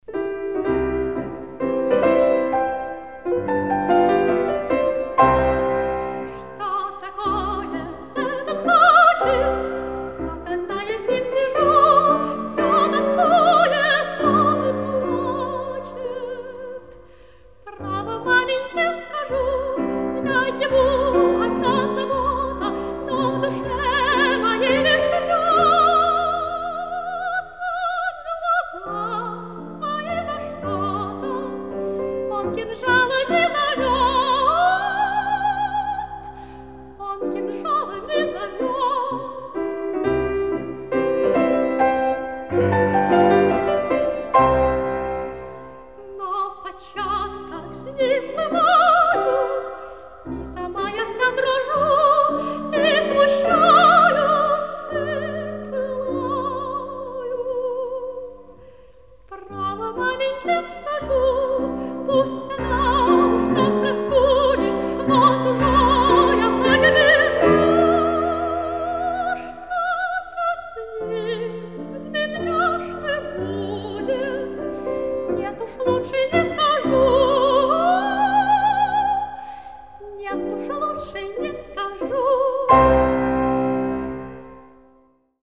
Инструментальное трио
альт-балалайка
бас-балалайка
баян